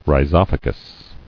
[rhi·zoph·a·gous]